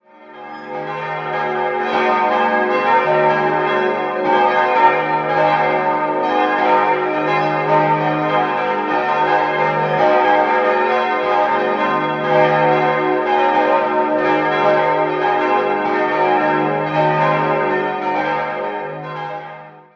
Der moderne Bau wurde schon bald zu einer der am häufigsten besuchten Kirchen in München und sorgte auch weit über die Grenzen der Landeshauptstadt hinaus für großes Aufsehen, aber auch sehr unterschiedliches Echo. Auf den Webseiten der Pfarrei kann man sich über das theologische Konzept des ungewöhnlichen Gotteshauses ausführlich informieren. 5-stimmiges Westminster-Geläute: es'-as'-b'-c''-es'' Die Glocken wurden von Rudolf Perner in Passau gegossen.